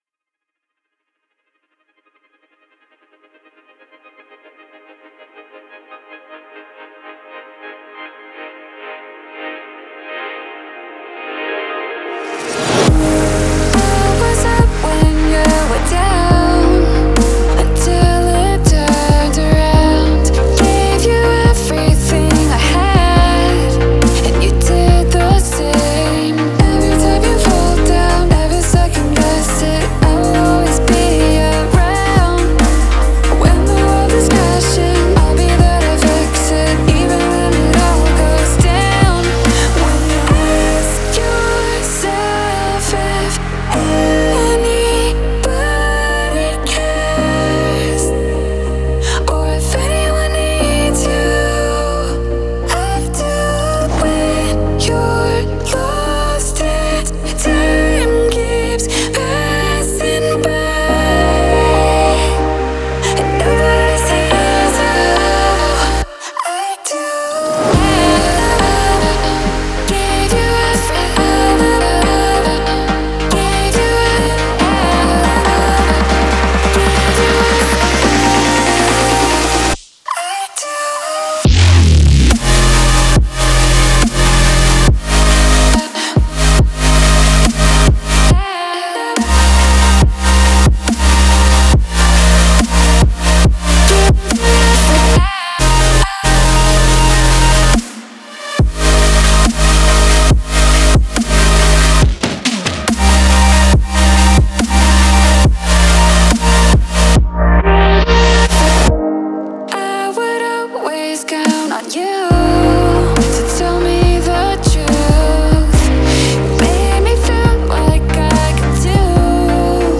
это захватывающая песня в жанре электронной музыки